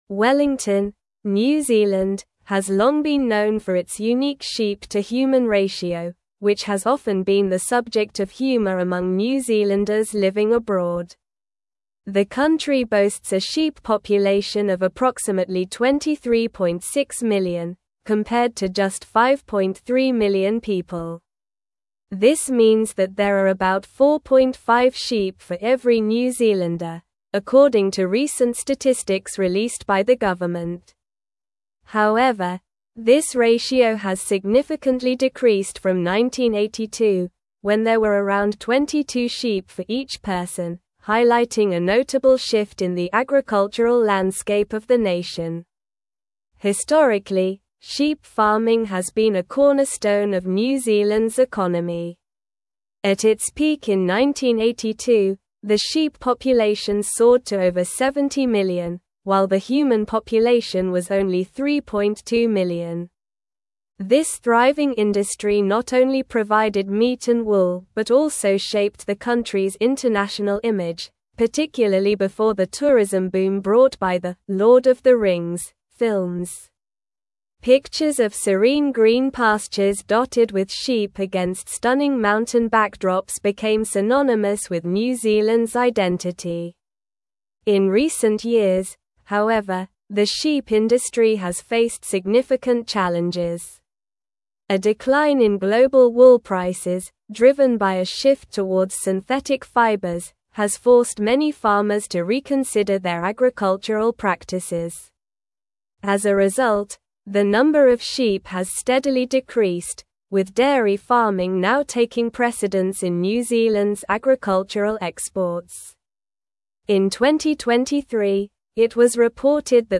English-Newsroom-Advanced-SLOW-Reading-Decline-of-New-Zealands-Sheep-Population-and-Industry.mp3